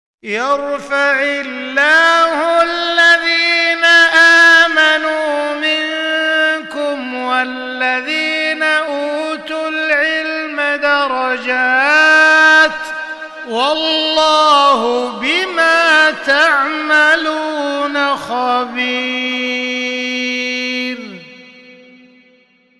قبس من تسجيلات المصحف المرتل لمعالي الشيخ أ.د. عبدالرحمن السديس في استديو الرئاسة. > مصحف الشيخ عبدالرحمن السديس ( تسجيل استديو رئاسة الحرمين ) > المصحف - تلاوات الحرمين